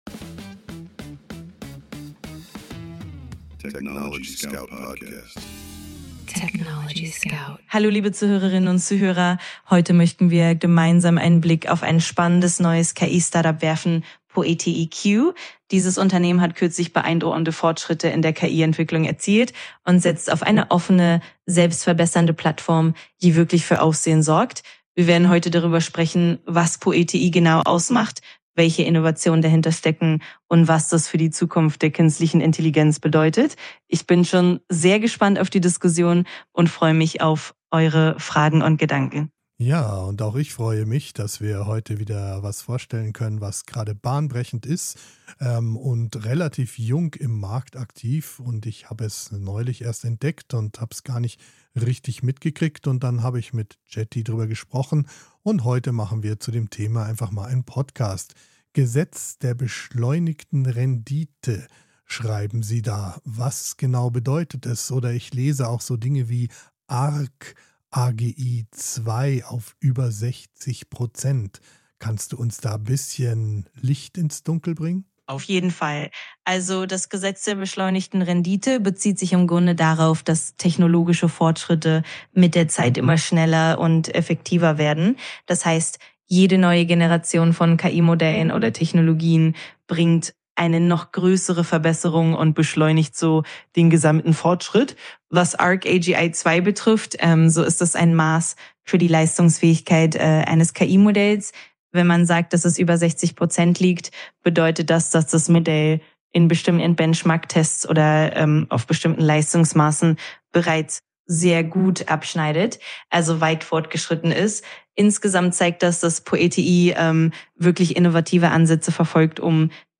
Mensch und KI sprechen miteinander – nicht gegeneinander.